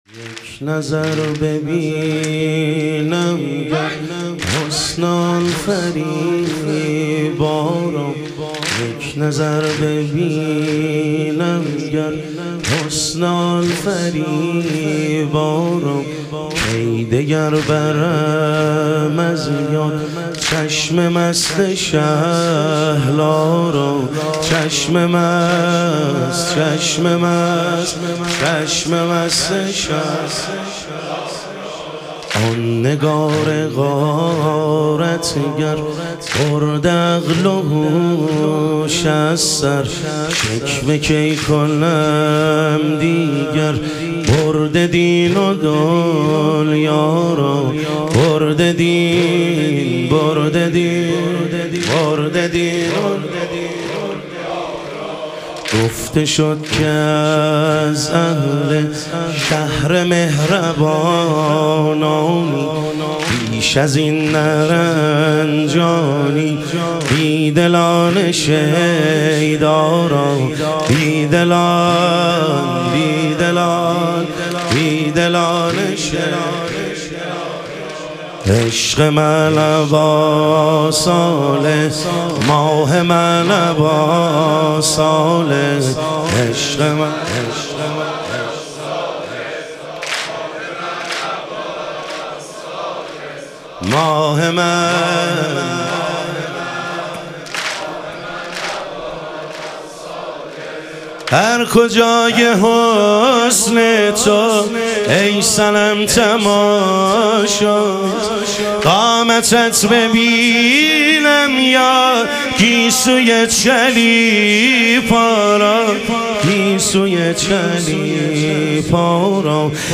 شهادت حضرت ام البنین علیها سلام - واحد